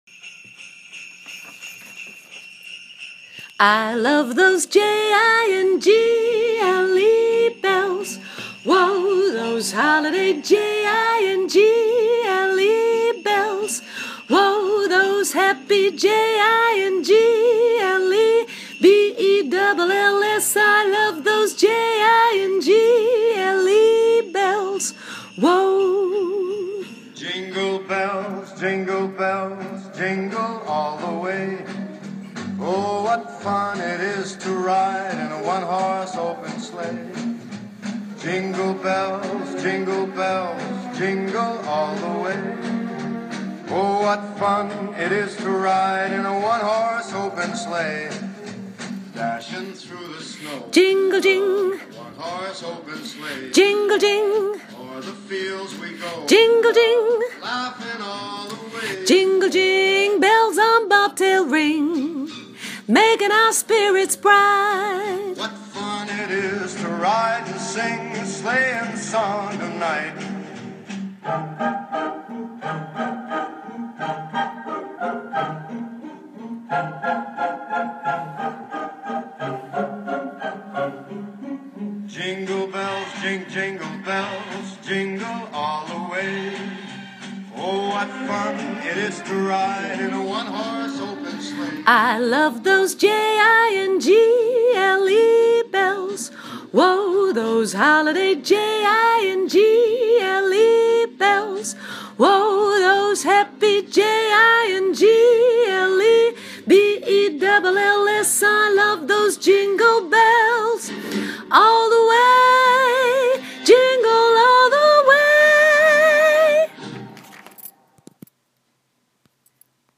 koortje sopraan